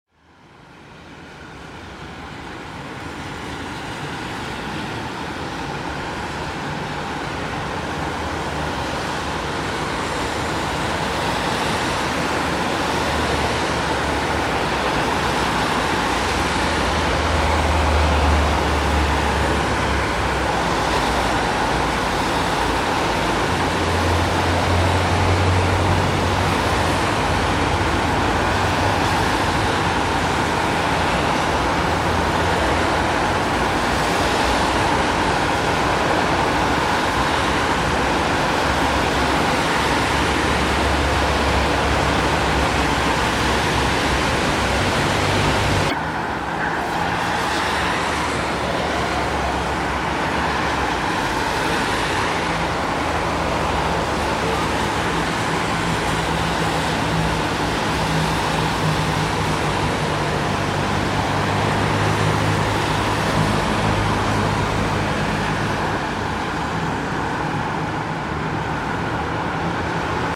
Constant whirr of loud traffic noise and gassy smells are less of a problem for coyotes than human and dog intrusions.
Busy traffic noise can be heard at denning sites close to busy roadways, and especially close to freeways: it results from tire friction and from the flow of air stirred up into a strong wind as each car travels: multiply this by the number of cars on the road and it can be loud, stressful and unsettling.
cut-traffic-noise-sound-effect.mp3